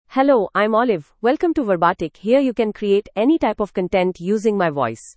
FemaleEnglish (India)
Olive is a female AI voice for English (India).
Voice sample
Listen to Olive's female English voice.
Olive delivers clear pronunciation with authentic India English intonation, making your content sound professionally produced.